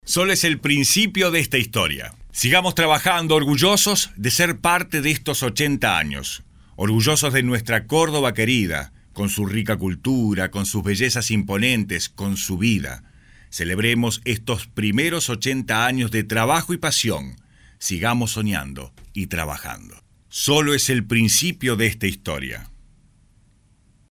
Lic. En comunicacion social, locutor de publicidades, programas de tv y elearning.
Buen todo, color y léxico. Expresivo, muy buena interpretación y emoción.
spanisch Südamerika
Good everything, color and lexicon. Expressive, very good interpretation and emotion.